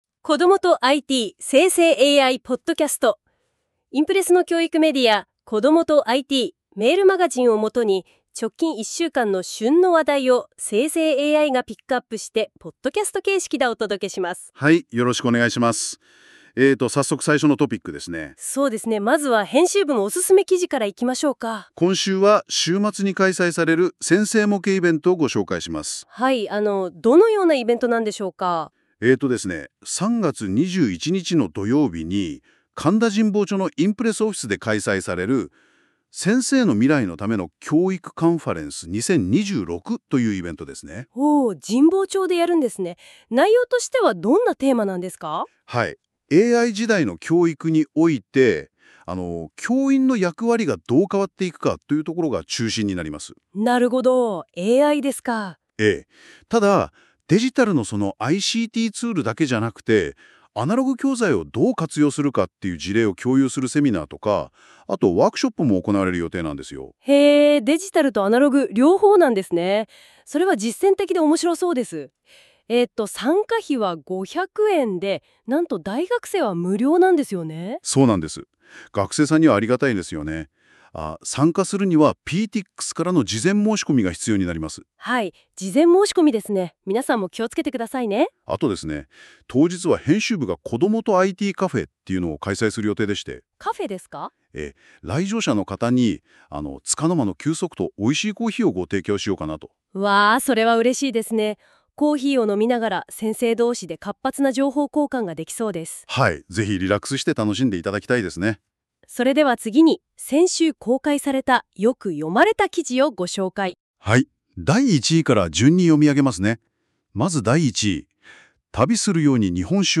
この記事は、『こどもとIT』メールマガジンを元に、先週１週間の旬の話題をNotebookLMでポッドキャストにしてお届けする、期間限定の実験企画です。 ※生成AIによる読み上げは、不自然なイントネーションや読みの誤りが発生します。 ※この音声は生成AIによって記事内容をもとに作成されています。